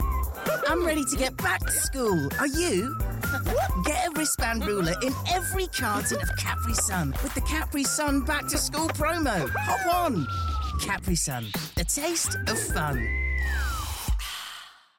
Englisch (Britisch)
Kommerziell, Junge, Natürlich, Verspielt, Freundlich
Persönlichkeiten